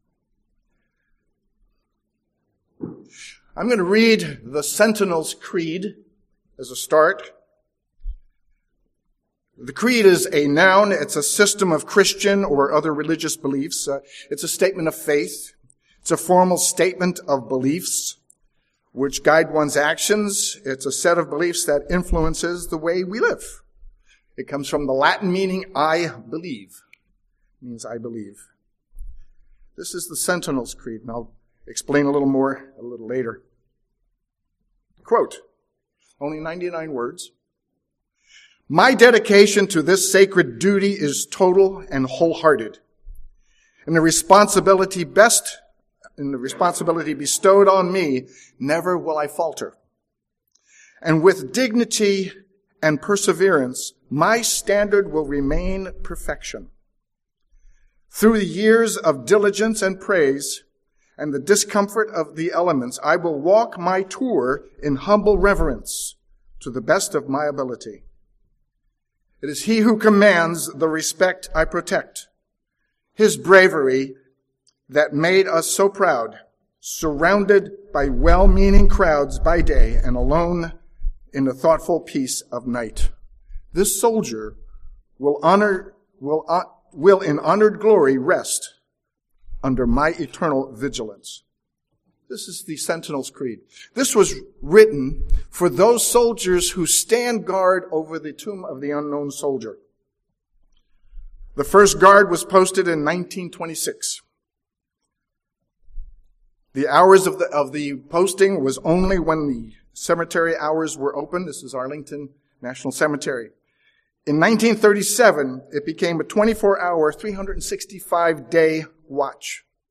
Listen to this sermon to learn about immersion and dedication to this way of life.
Given in San Jose, CA